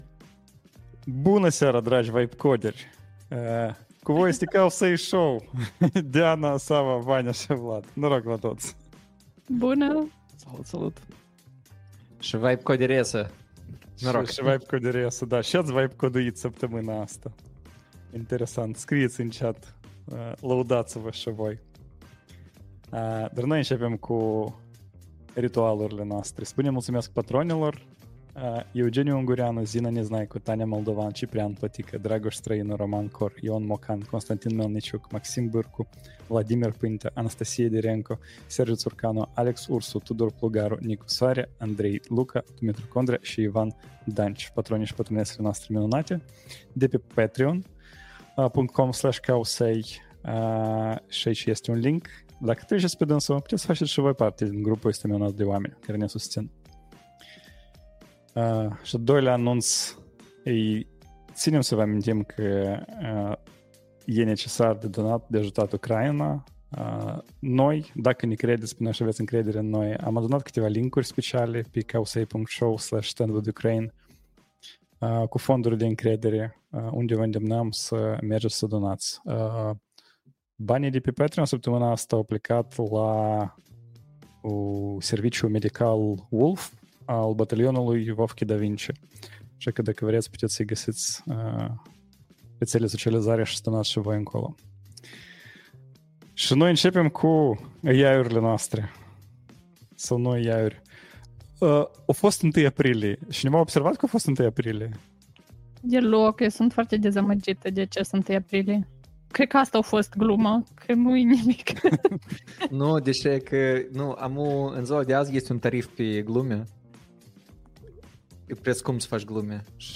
Podcast Moldovenesc depsre IT, business și oameni
Live 141: 1 Aprilie sau investiții 🤑 nemaipomenite April 04th, 2025 Live-ul săptămânal Cowsay Show. Vă aducem o doză [ne]sănătoasă de speculații 🤗.